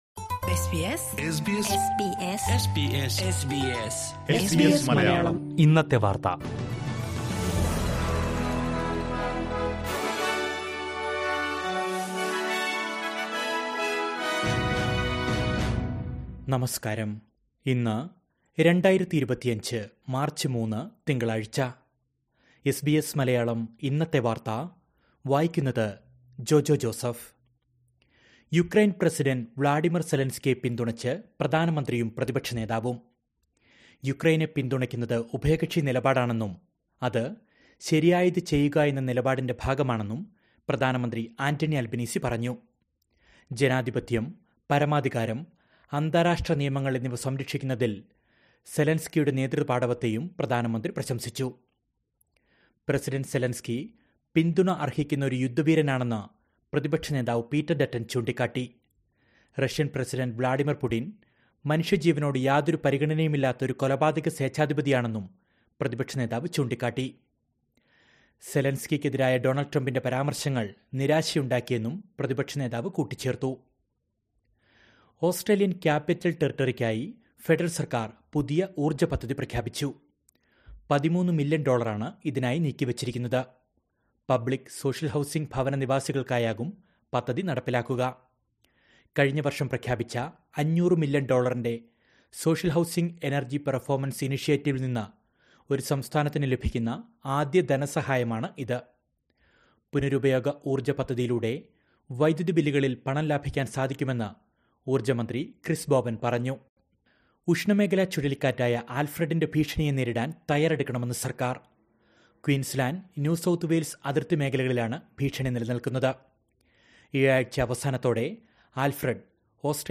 2025 മാർച്ച് മൂന്നിലെ ഓസ്‌ട്രേലിയയിലെ ഏറ്റവും പ്രധാന വാര്‍ത്തകള്‍ കേള്‍ക്കാം...